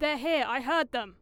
Added all voice lines in folders into the game folder